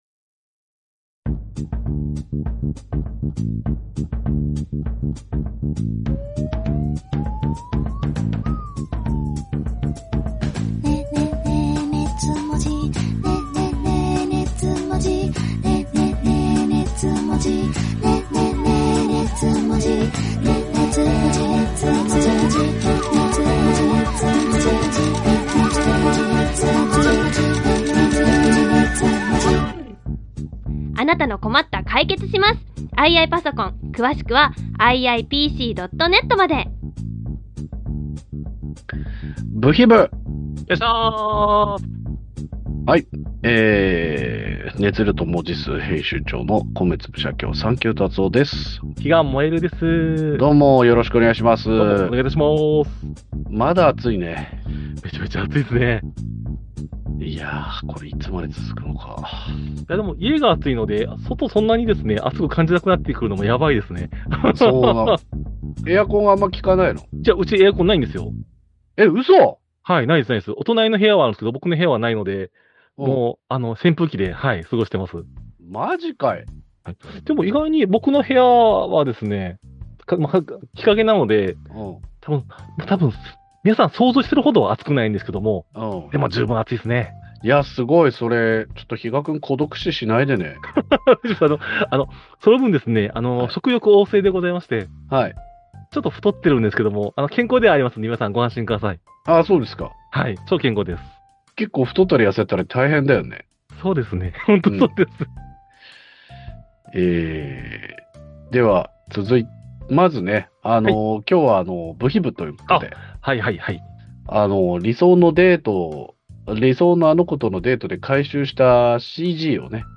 二次元を哲学するトークバラエティ音声マガジン